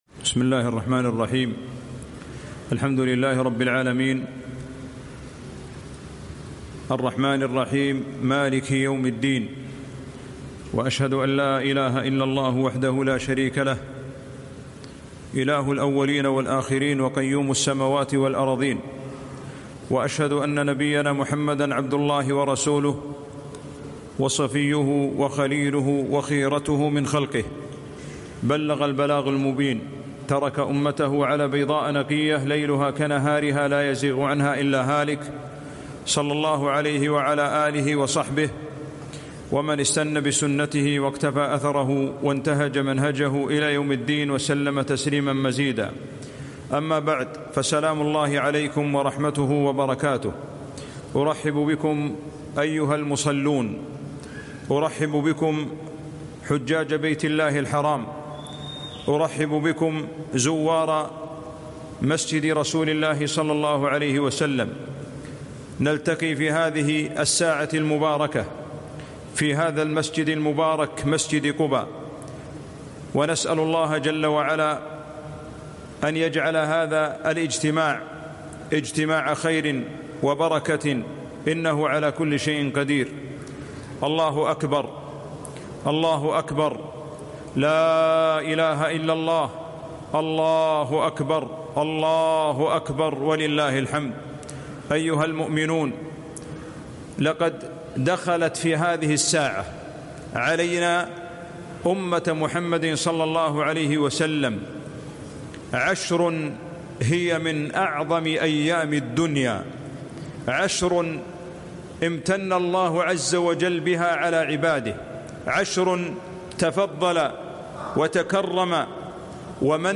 كلمة - اغتنموا أفضل أيام الدنيا.